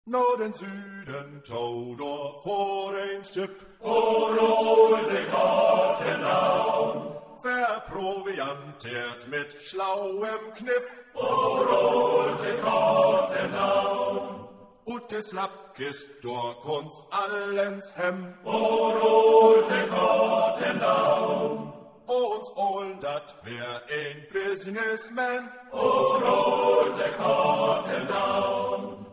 Chorgesang pur